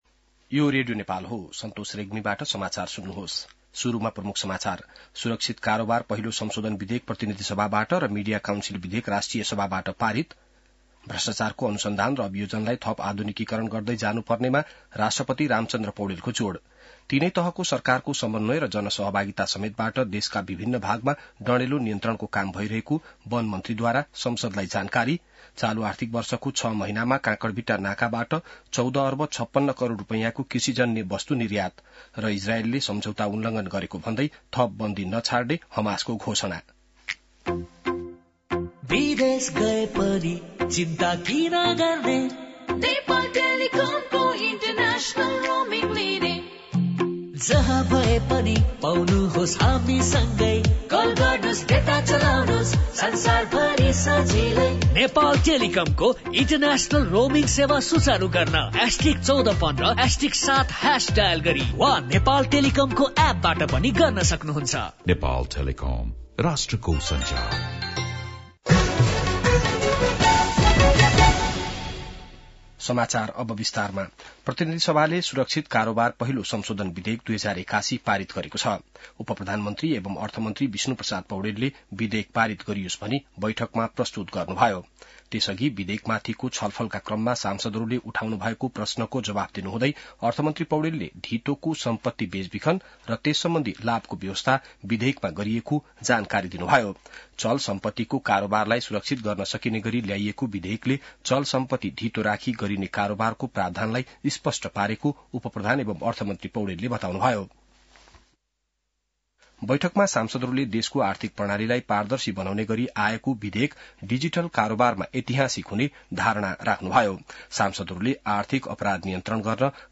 बिहान ७ बजेको नेपाली समाचार : ३० माघ , २०८१